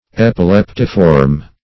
Epileptiform \Ep`i*lep"ti*form\, a.
epileptiform.mp3